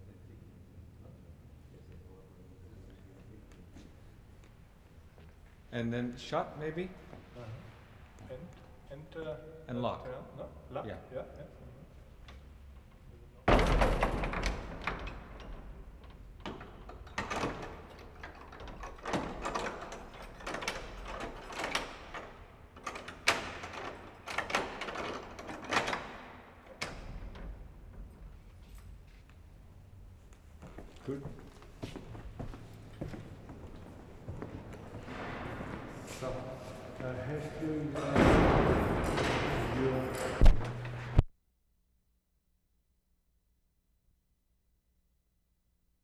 mark * leaving reading room, followed quickly by a sequence in the State Hall conversation, pause, walk out and locking the large doors, a) from the inside, b) from the outside.
15-16. Excellent examples of changing ambience and heavy wooden doors opening and closing.